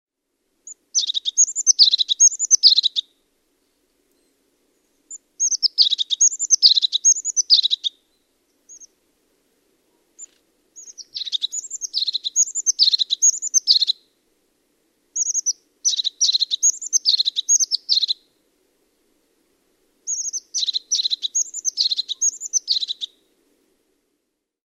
Kuuntele töyhtötiaisen laulua